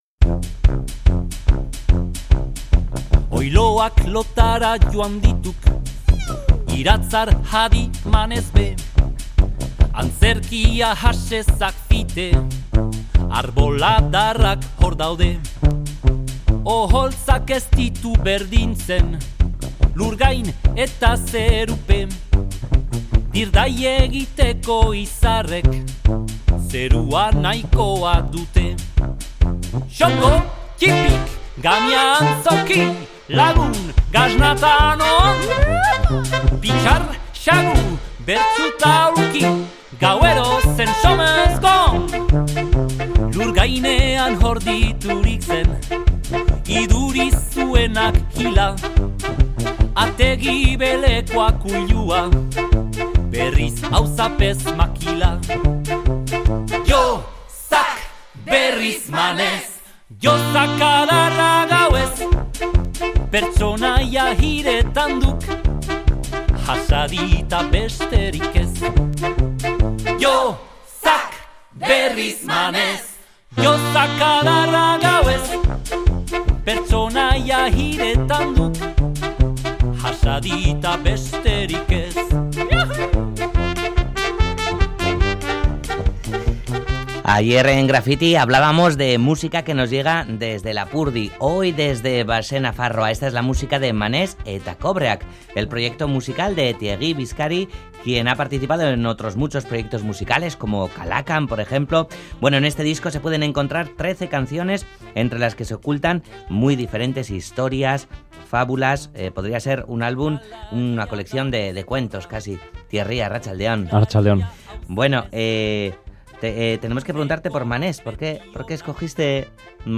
toda una orquesta de vientos y voces